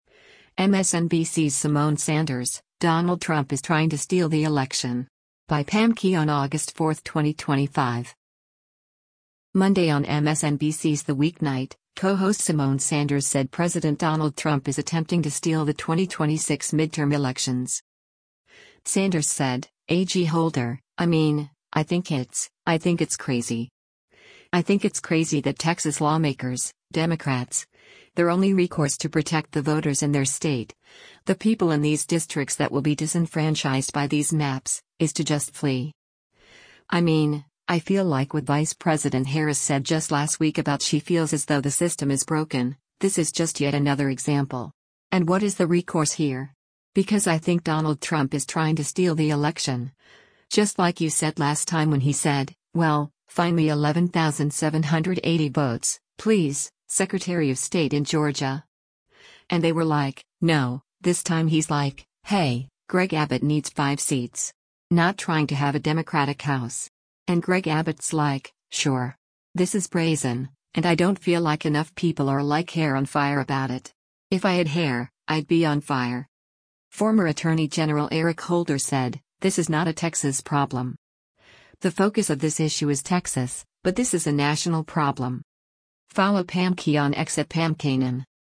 Monday on MSNBC’s “The Weeknight,” co-host Symone Sanders said President Donald Trump is attempting to “steal” the 2026 midterm elections.